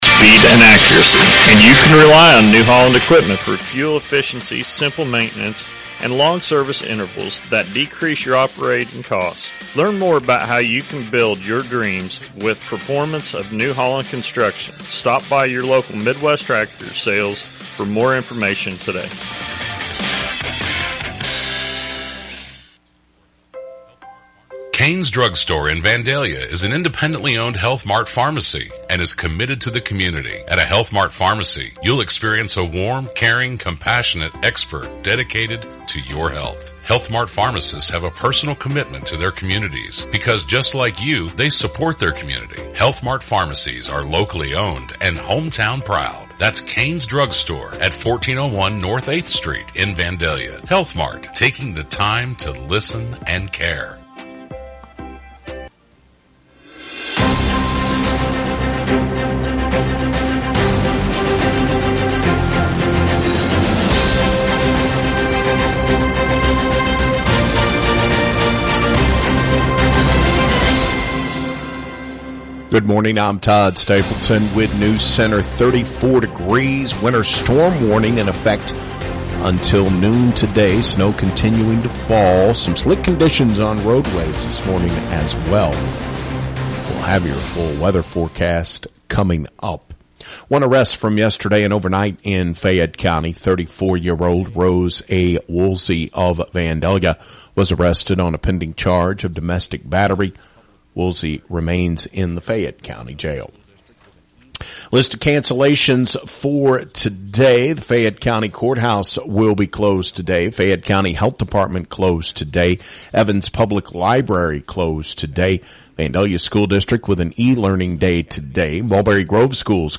On Demand–Newscast for 1-25-23
newscast-for-1-25-23.mp3